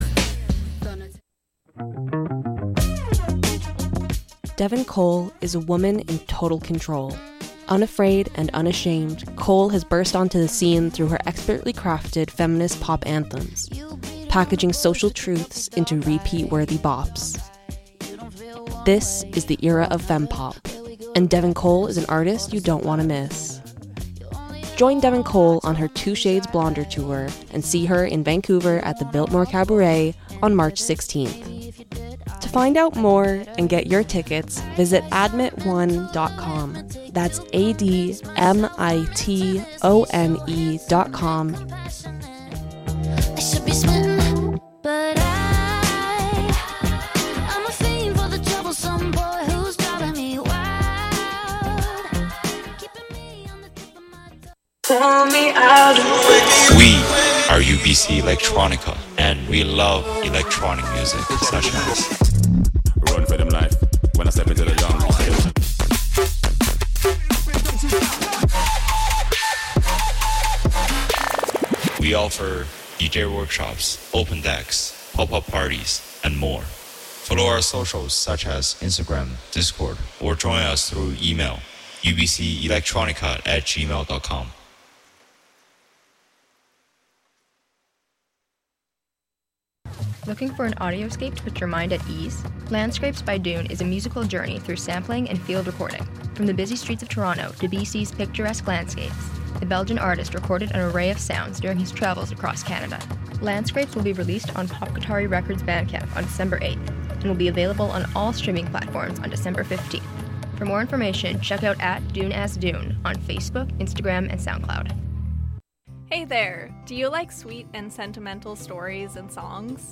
on the eve of the least important night of their lives, soundwave entities coalesce in a profligate effusion of dust and noise ..